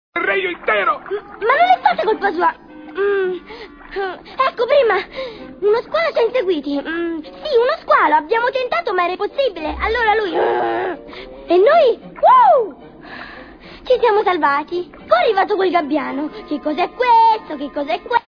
FILM D'ANIMAZIONE (CINEMA E HOME-VIDEO)
• "La Sirenetta" (Voce di Flounder)